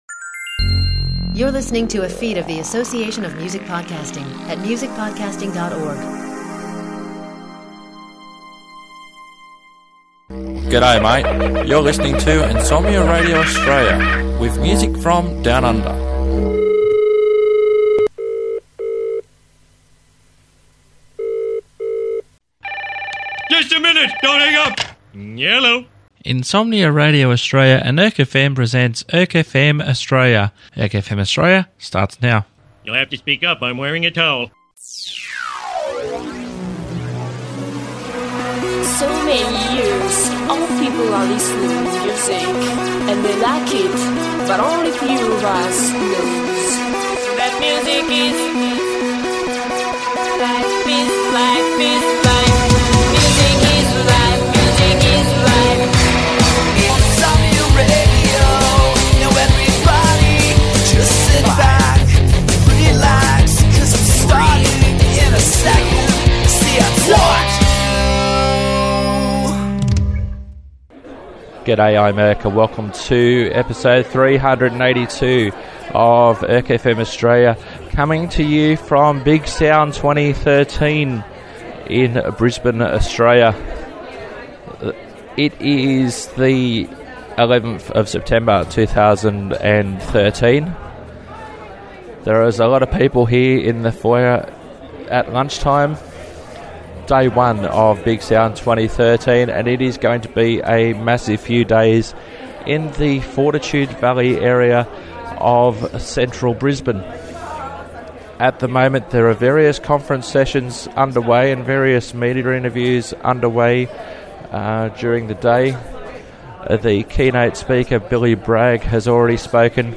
On this week's episode, there are interviews conducted over 2 days of various artists.